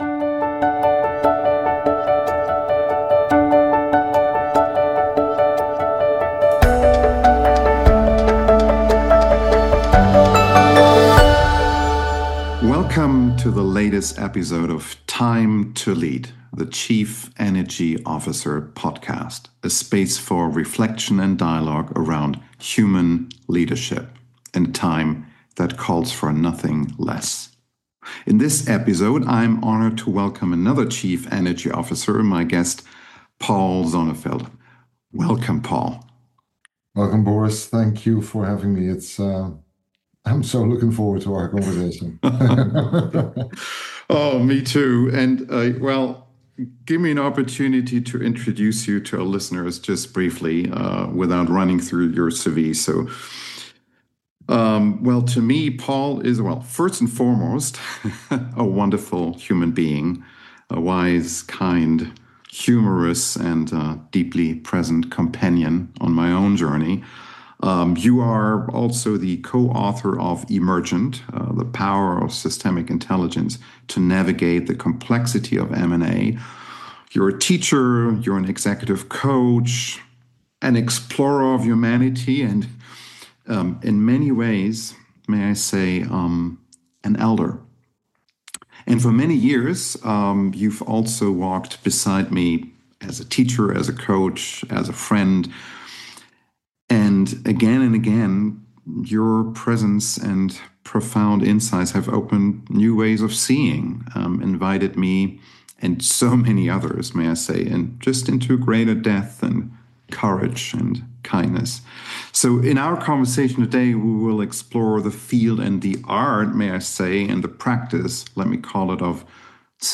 Together, we dive into hands-on daily practices that help leaders access hidden knowledge — tapping into the deeper wisdom available in every meeting, every moment. Listen in to a truly rich dialogue, anchored in systemic intelligence and grounded in the everyday reality of leadership – the life of Chief Energy Officers.